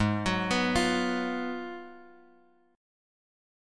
G#m13 Chord
Listen to G#m13 strummed